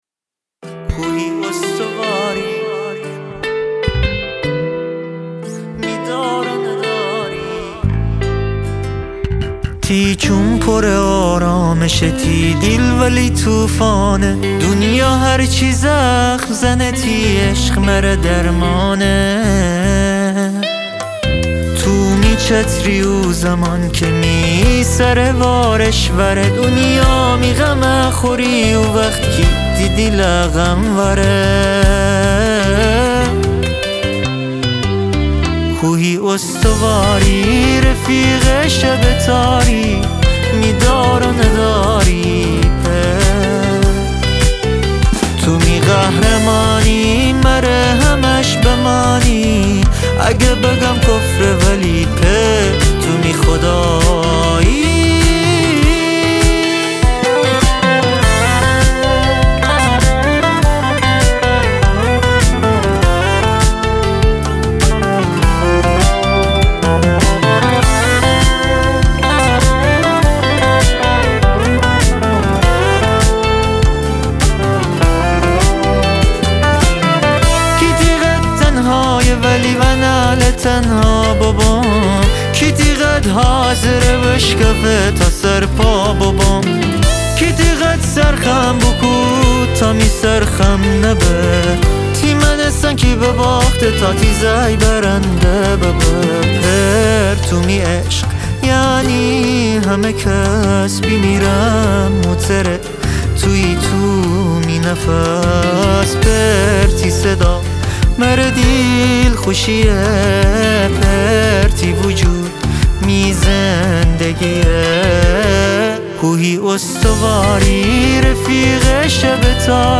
خواننده جوان لشت نشائی
گیتار الکتریک